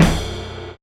Kicks
Kick Koopa 2.wav